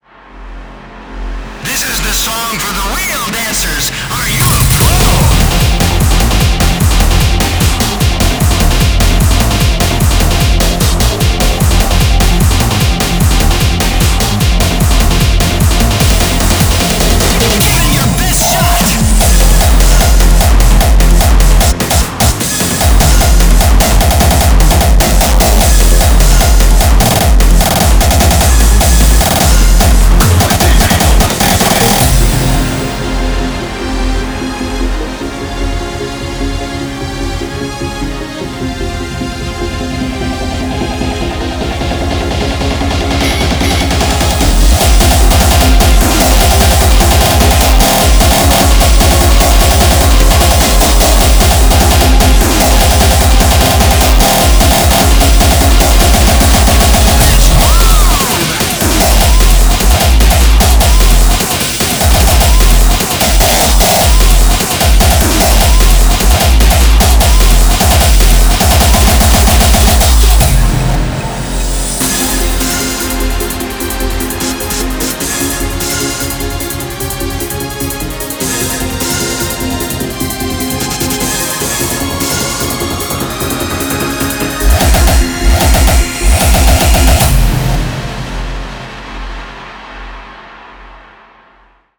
BPM150-300
Also been remastered compared to the one on my Soundcloud: